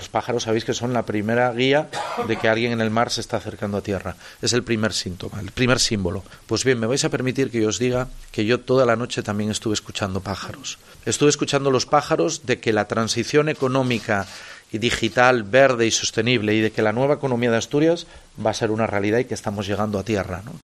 Barbón hacía estas declaraciones durante la presentación del proyecto que impulsan EXIOM e Iberdrola para instalar una planta de fabricación de placas solares en la antigua factoría de Vesuvius en Langreo.